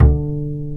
Index of /90_sSampleCDs/Roland L-CDX-01/BS _Jazz Bass/BS _Acoustic Bs